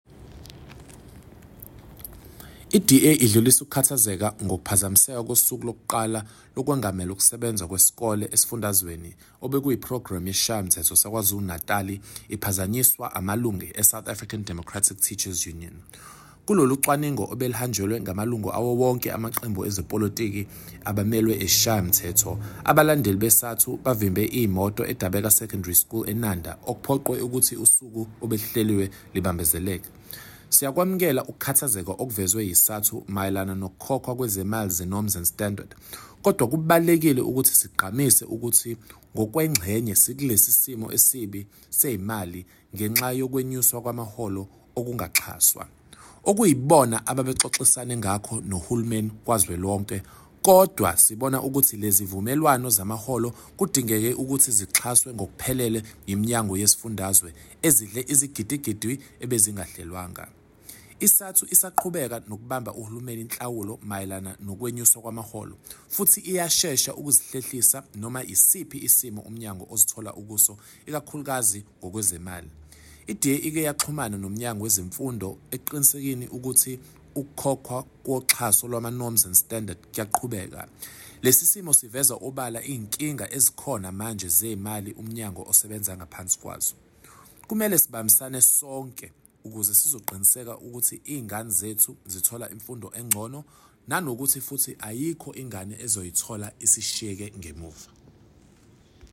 Note to Editors: Please note Sakhile Mngadi, MPL sound bites in